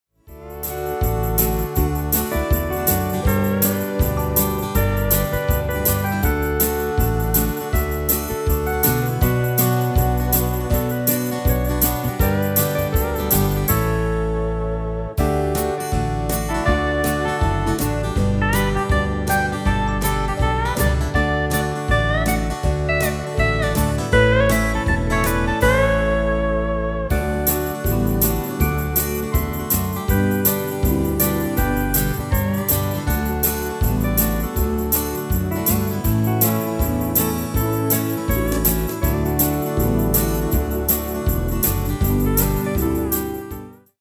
Demo/Koop midifile
Genre: Country & Western
Toonsoort: D
- Vocal harmony tracks
Demo's zijn eigen opnames van onze digitale arrangementen.